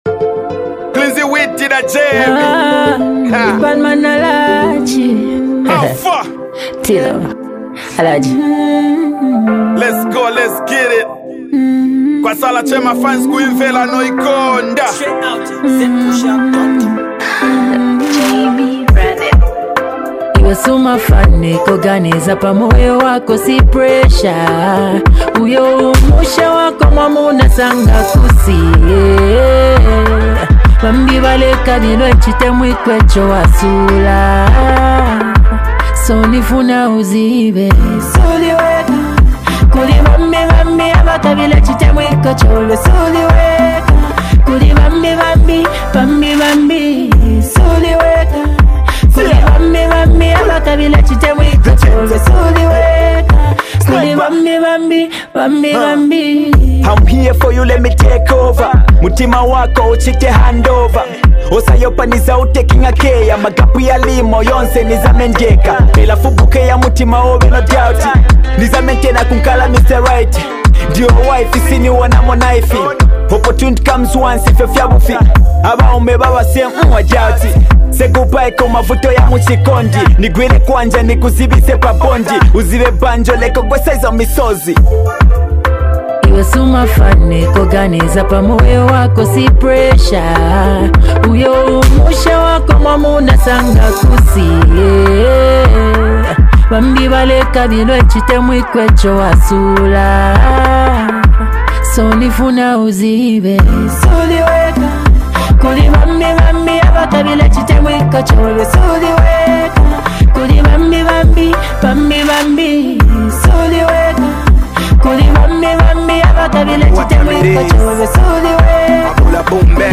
Get the love joint below and add it to your playlist.